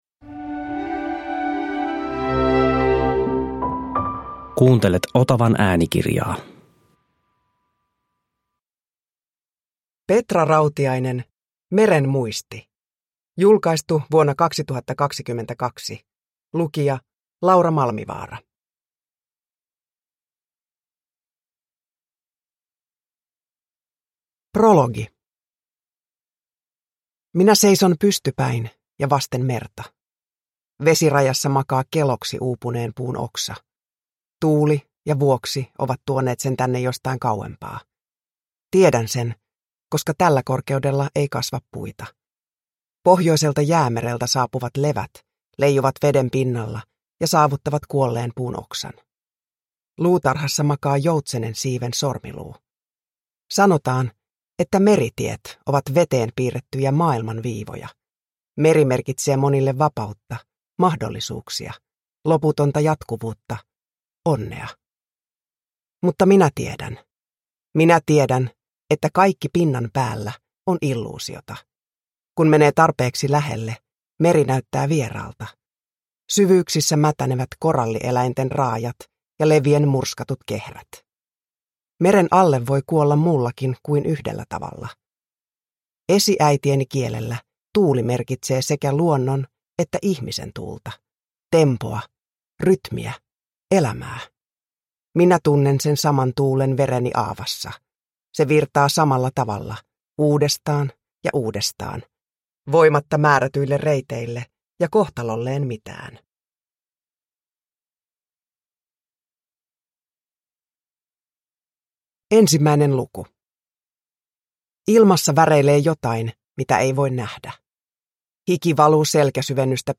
Meren muisti – Ljudbok – Laddas ner
Uppläsare: Laura Malmivaara